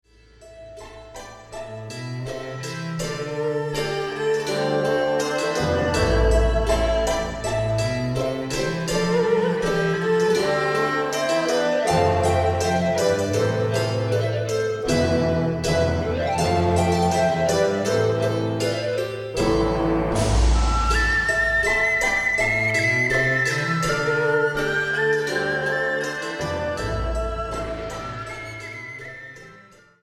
chinese bamboo flute, special effects
orchestra
- Recorded and mixed at AVAF Studios, Zurich, Switzerland